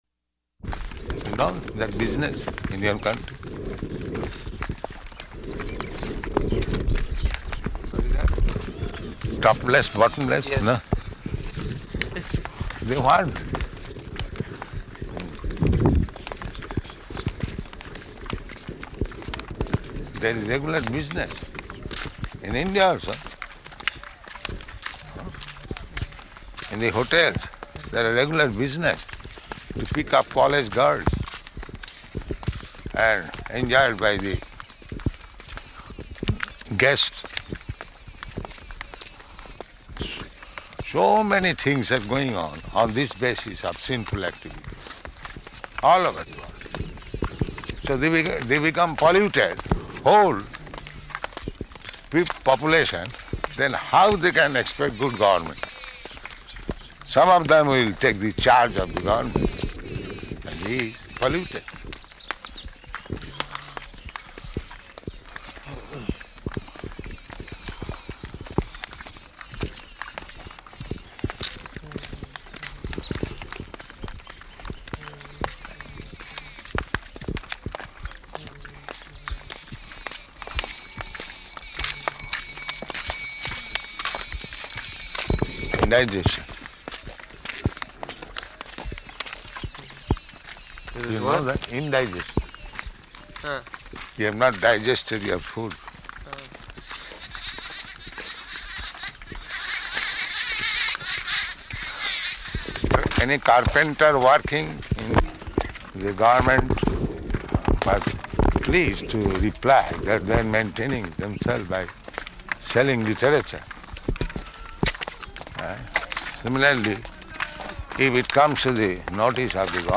Morning Walk --:-- --:-- Type: Walk Dated: March 13th 1974 Location: Vṛndāvana Audio file: 740313MW.VRN.mp3 Prabhupāda: You know that business in your country.